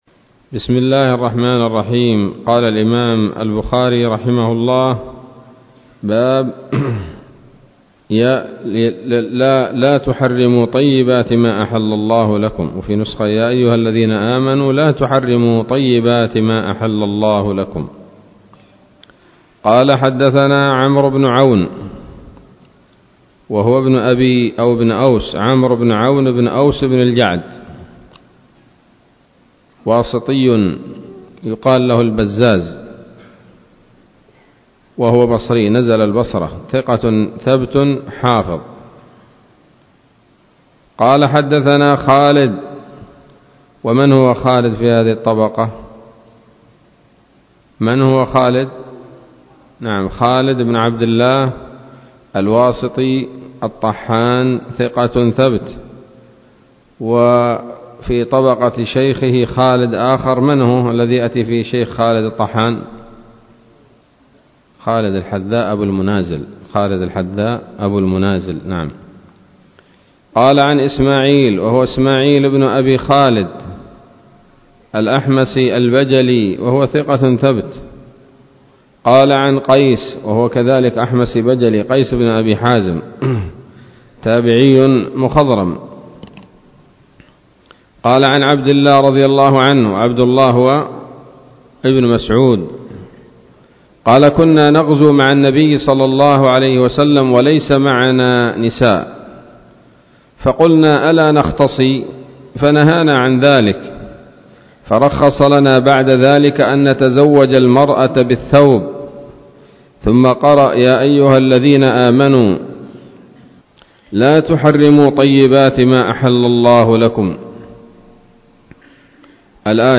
الدرس الثاني والتسعون من كتاب التفسير من صحيح الإمام البخاري